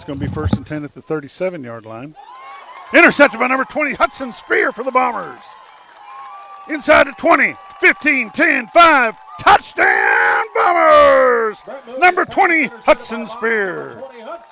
Here’s how it sounded on the Bomber Sports Network.